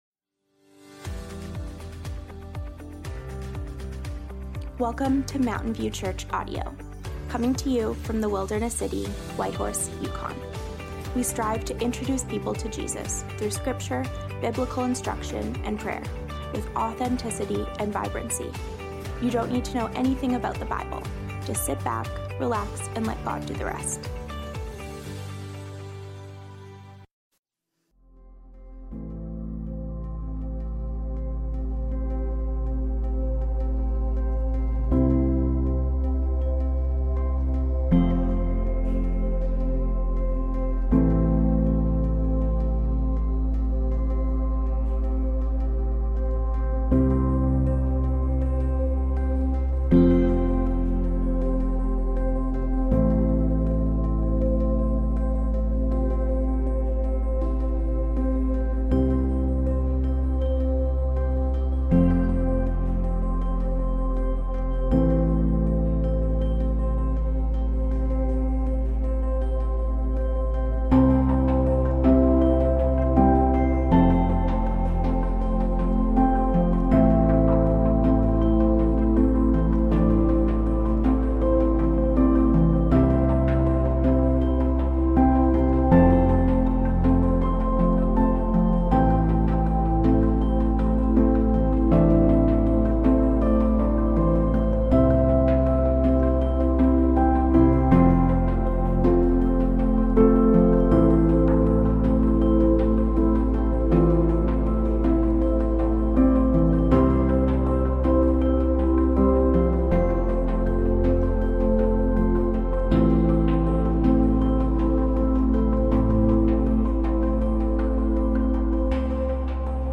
Who Has Authority? (Psalms, Ep. 9 - Sermon Audio)